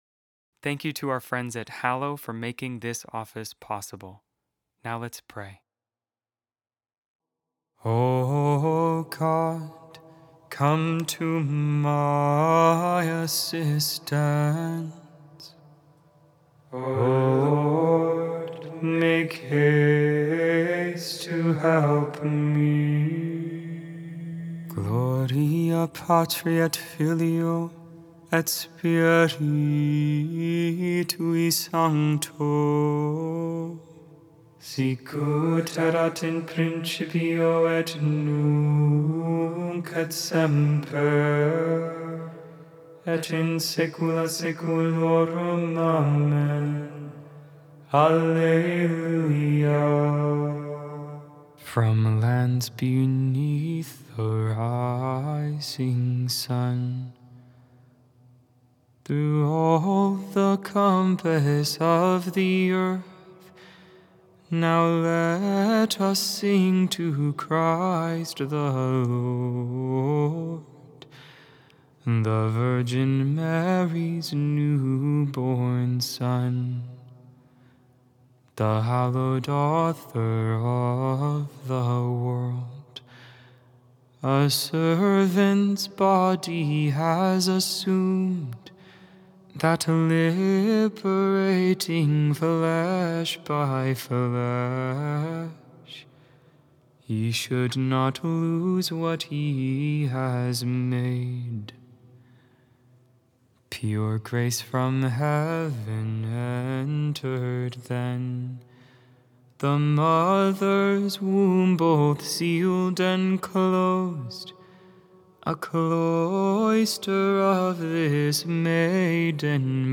Lauds, Tuesday Morning Prayer of the Liturgy of the Hours, December 30th, 2025. 6th Day in the Octave of Christmas Made without AI. 100% human vocals, 100% real prayer.